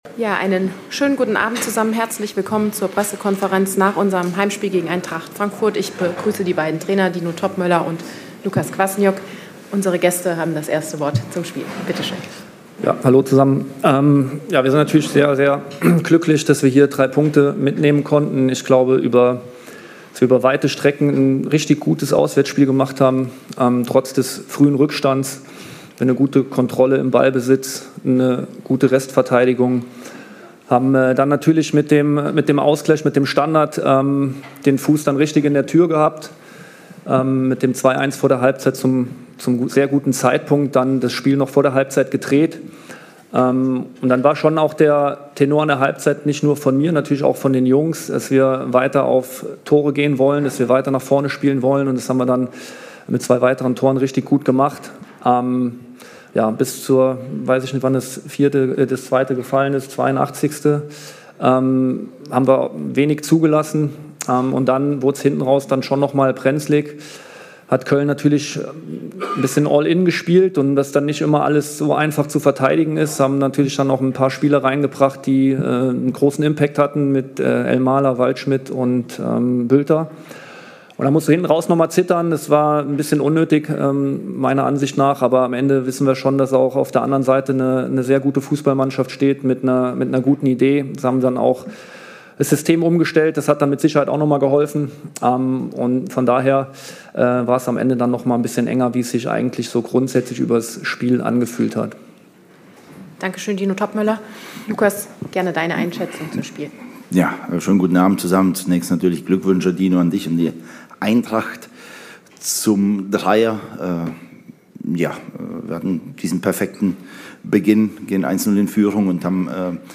Die beiden Cheftrainer Dino Toppmöller und Lukas Kwasniok auf der Pressekonferenz nach dem intensiven 4:3-Auswärtssieg in Köln.